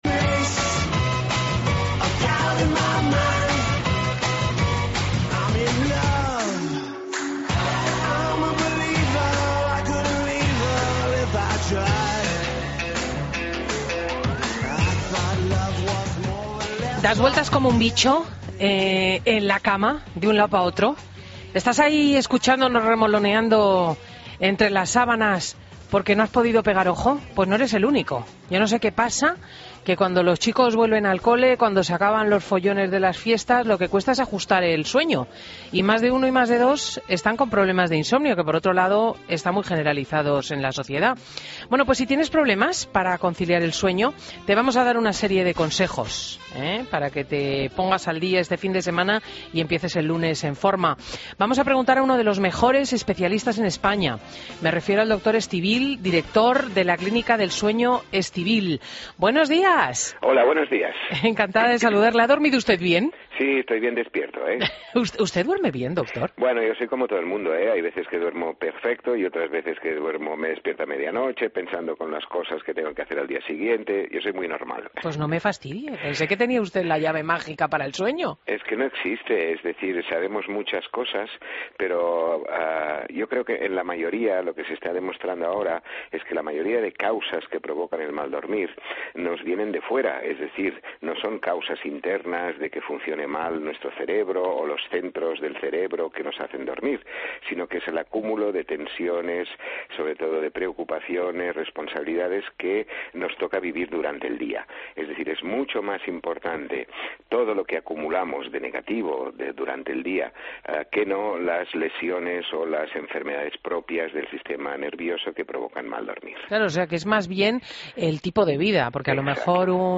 AUDIO: Entrevista al Doctor Estivill en Fin de Semana